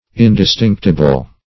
Search Result for " indistinctible" : The Collaborative International Dictionary of English v.0.48: Indistinctible \In`dis*tinc"ti*ble\ ([i^]n`d[i^]s*t[i^][ng]k"t[i^]*b'l), a. Indistinguishable.